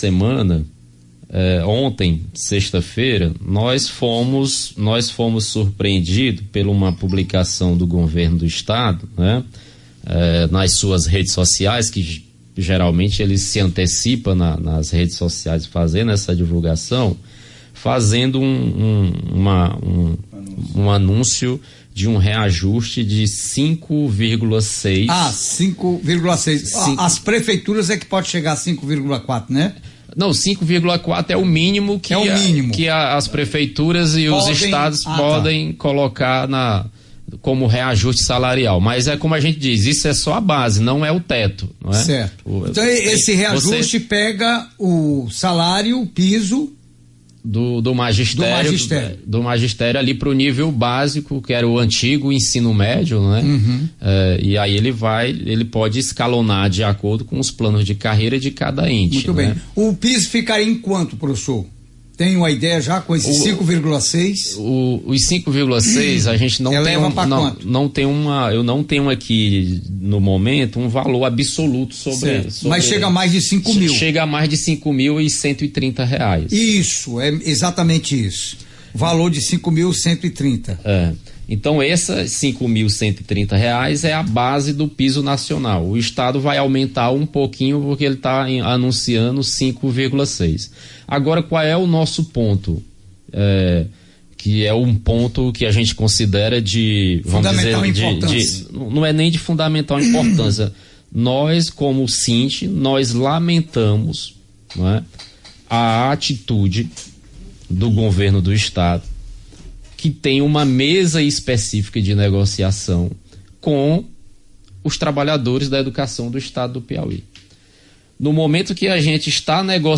ENTREVISTA REAJUSTE SALARIAL